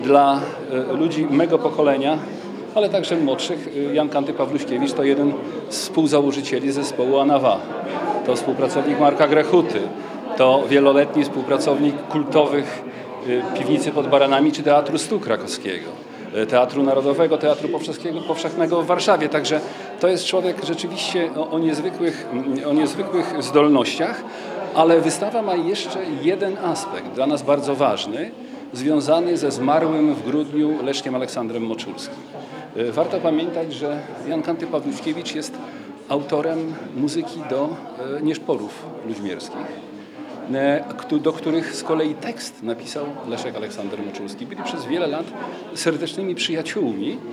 mówił podczas wernisażu